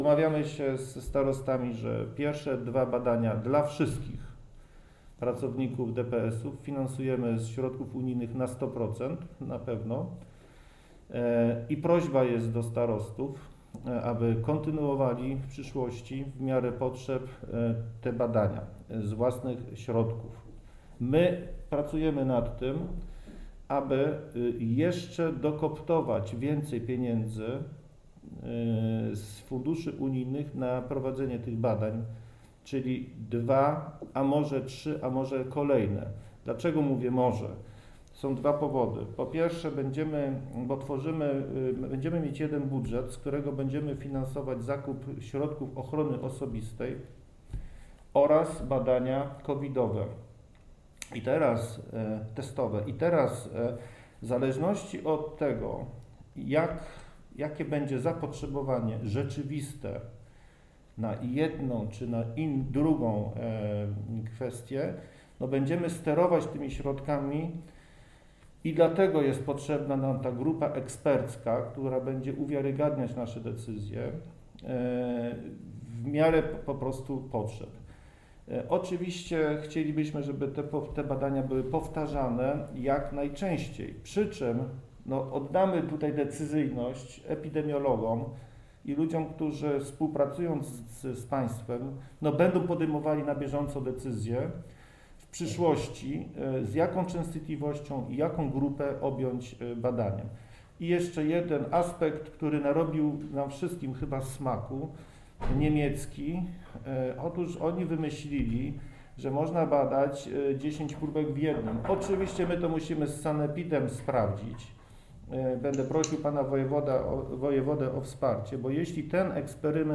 Wypowiedź marszałka Piotra Całbeckiego z wideokonferencji (audio)